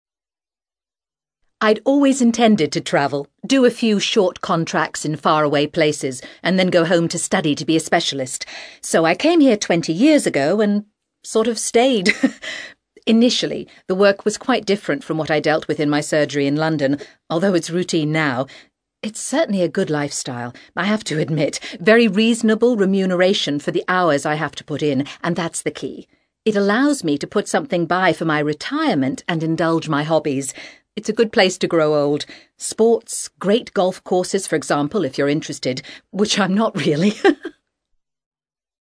ACTIVITY 112: You will hear five short extracts in which British people are talking about living abroad.